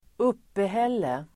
Uttal: [²'up:ehel:e]